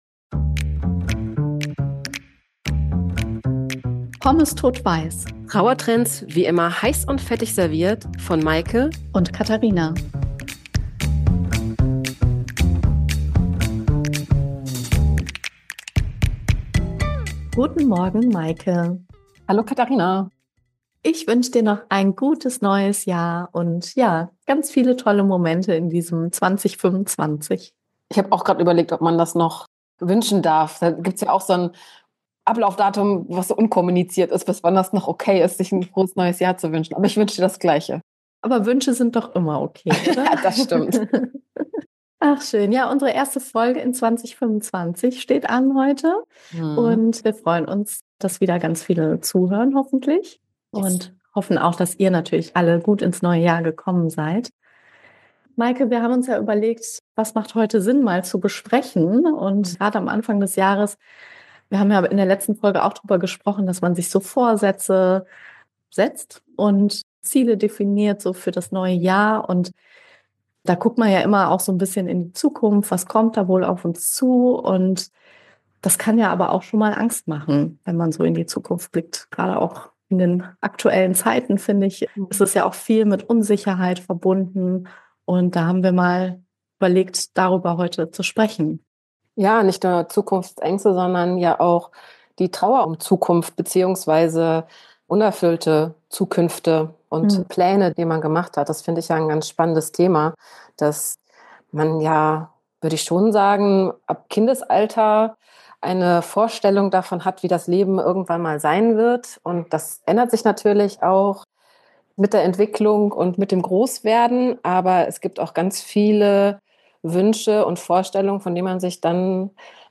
Zwei Trauerbegleiterinnen, die finden dass Leichtigkeit für Trauer wie das Salz für Pommes ist.